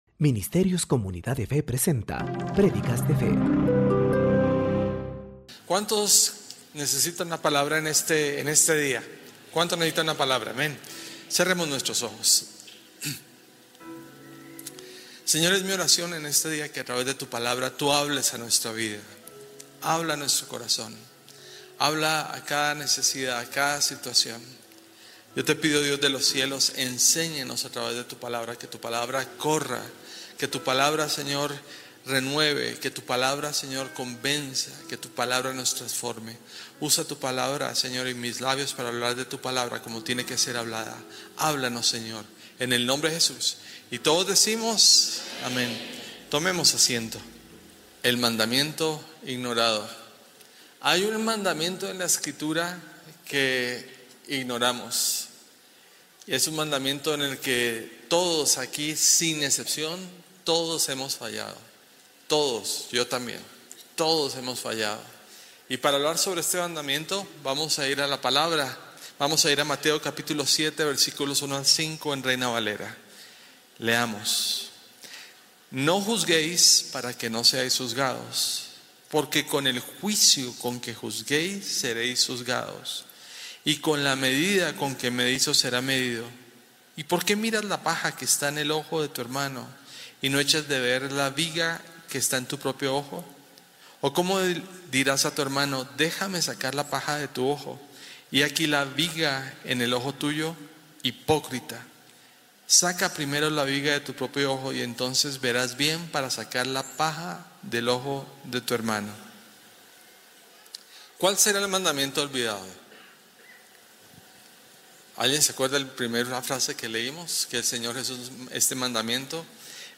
Comunidad de Fe